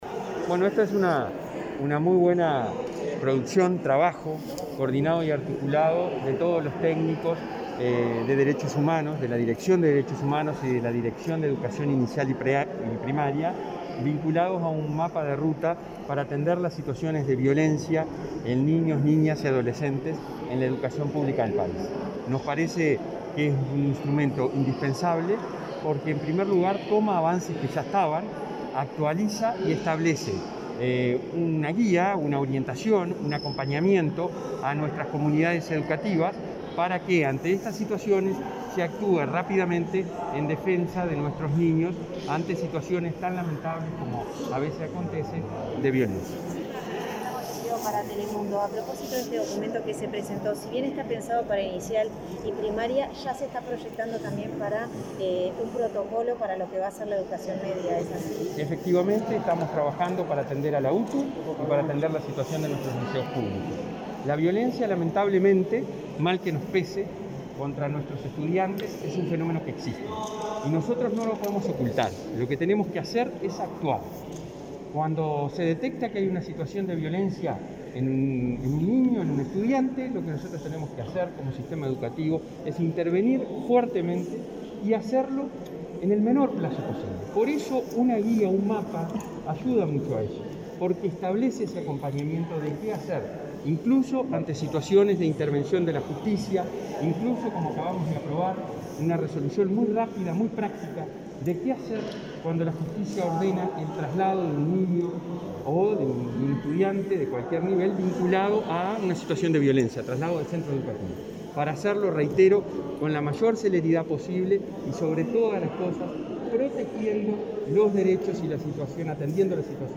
Declaraciones del presidente de ANEP, Robert Silva, a la prensa